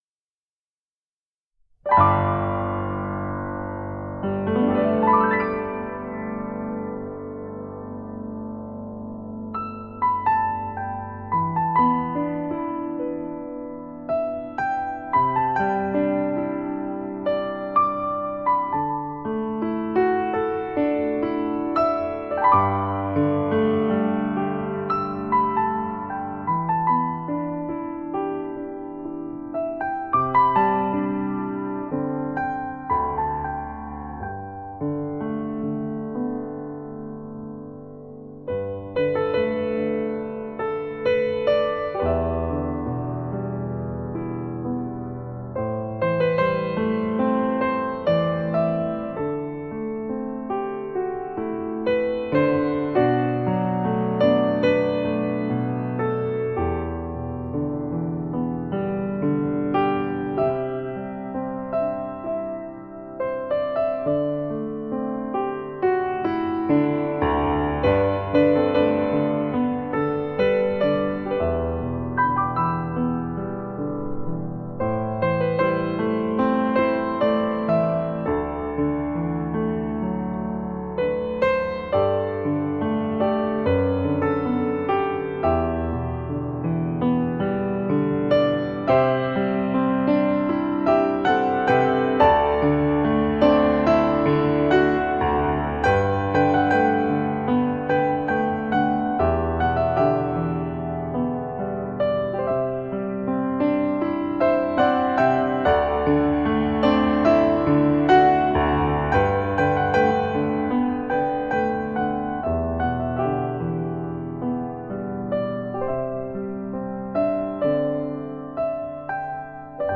手指间弹动的是心之弦